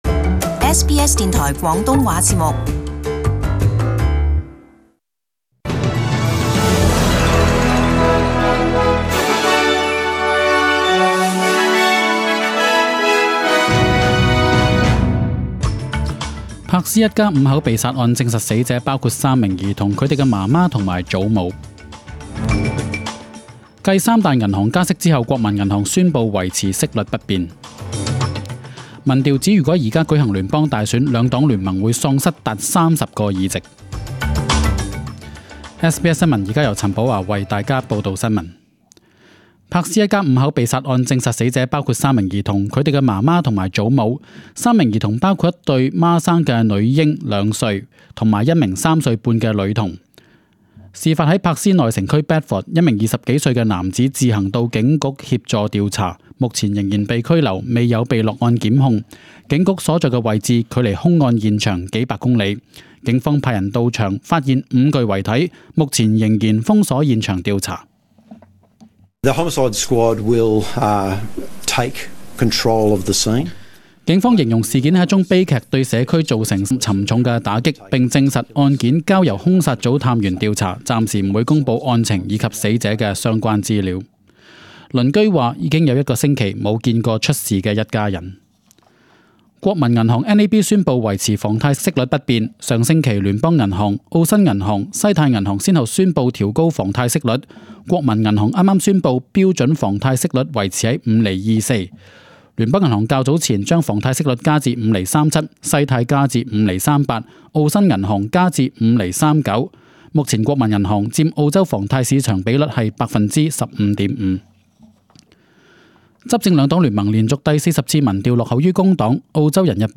十点钟新闻 (10 月 9 日) 13:00 Cantonese News Source: SBS SBS广东话播客 View Podcast Series Follow and Subscribe Apple Podcasts YouTube Spotify Download (23.82MB) Download the SBS Audio app Available on iOS and Android 详细新闻内容。